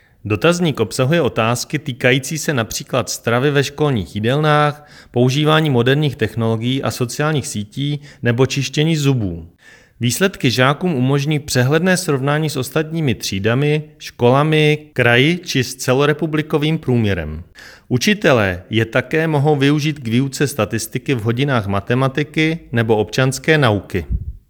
Vyjádření předsedy ČSÚ Marka Rojíčka, soubor ve formátu MP3, 663.31 kB